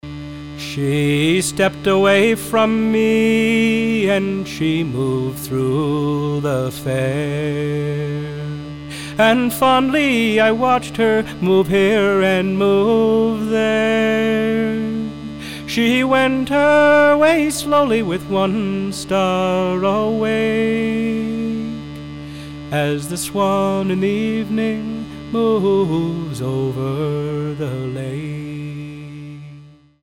- well known Irish Air, sung to a piper’s drone